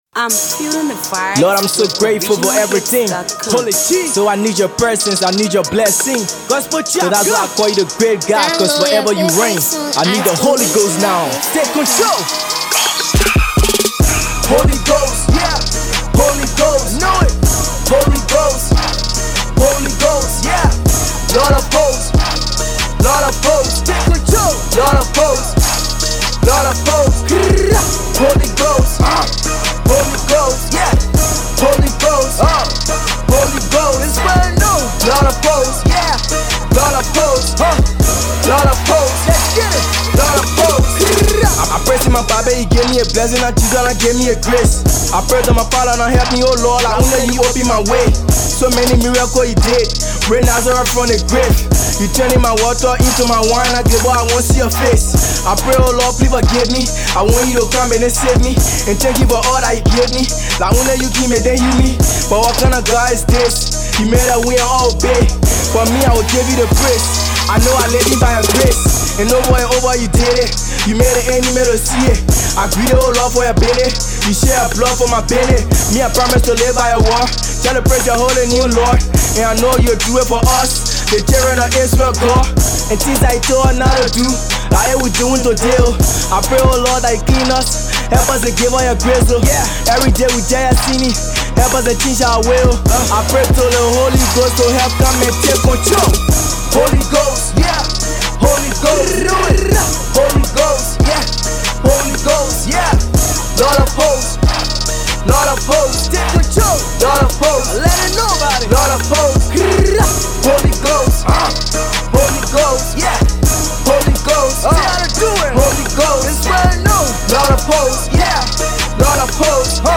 Gospel trap song